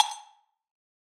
Woodblock Zion.wav